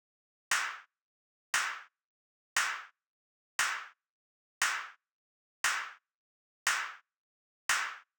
11 Clap.wav